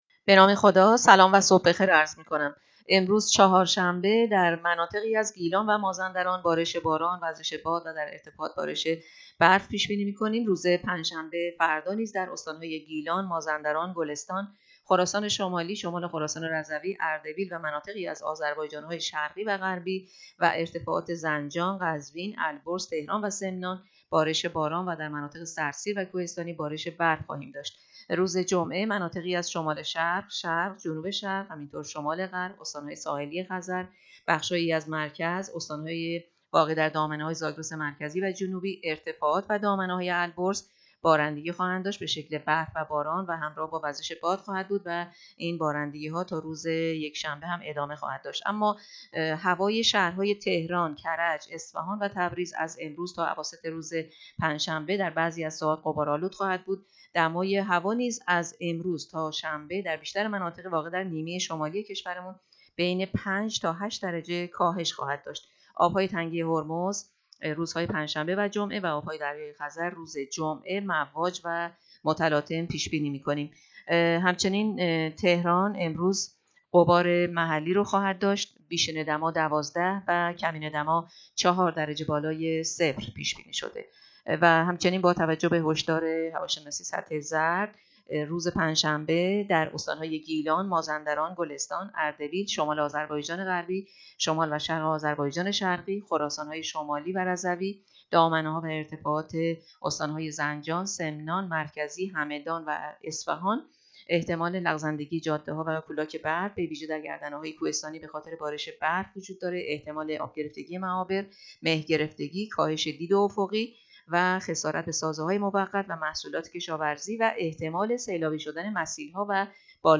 گزارش رادیو اینترنتی پایگاه‌ خبری از آخرین وضعیت آب‌وهوای ۲۶ دی؛